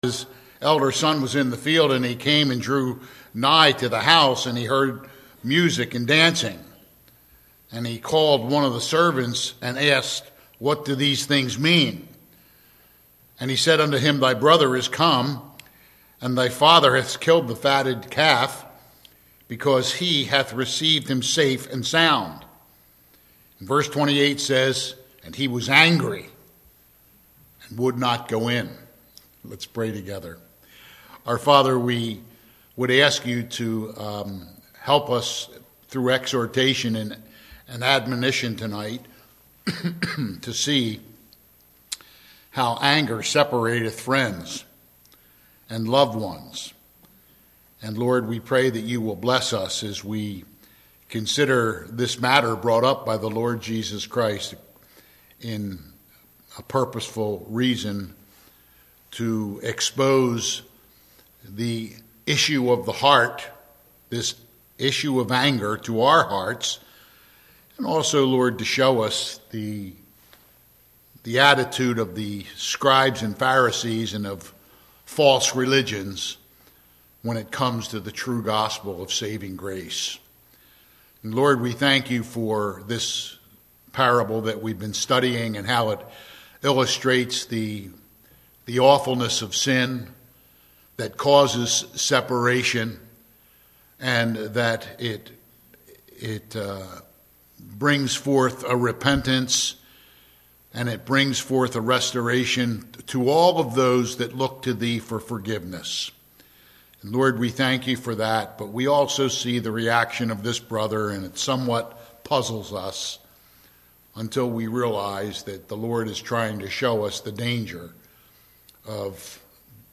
Passage: Luke 15:28 Service Type: Sunday PM « February 25